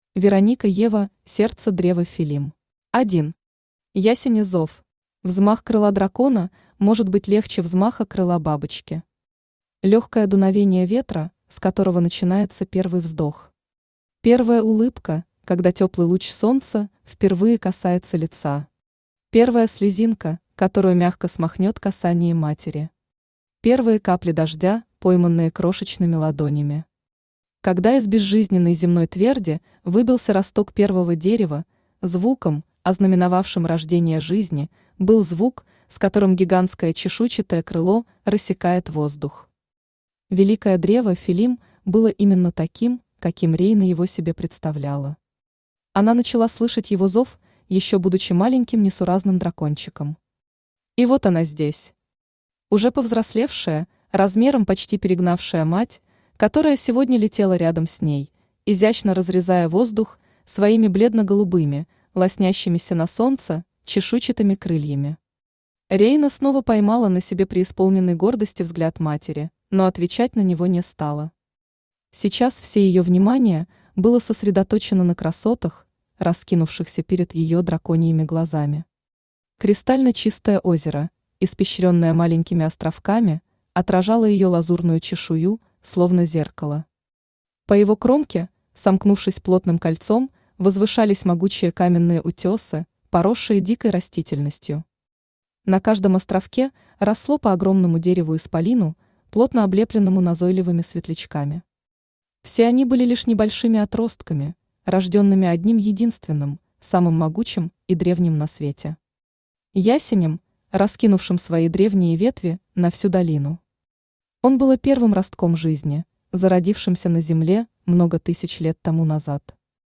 Aудиокнига Сердце древа Филлим Автор Вероника Ева Читает аудиокнигу Авточтец ЛитРес.